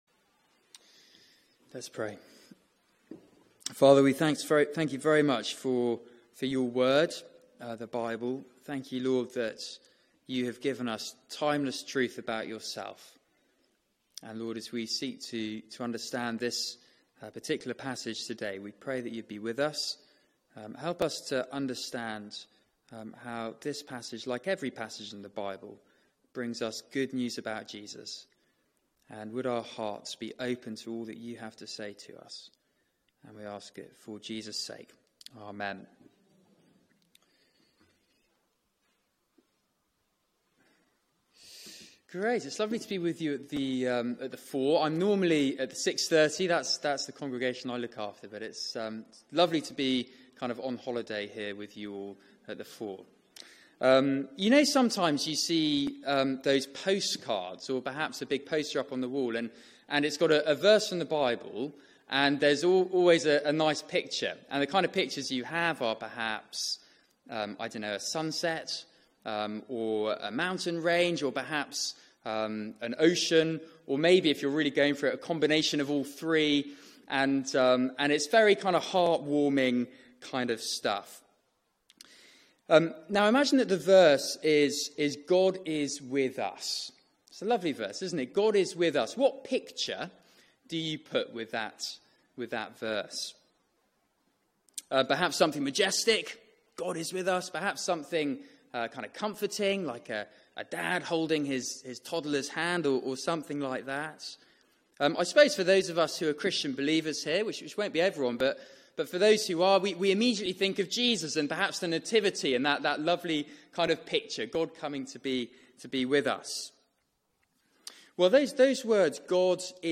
Media for 4pm Service on Sun 29th Oct 2017 16:00 Speaker
Series: The Triumph of Grace Theme: God brings Judgment Sermon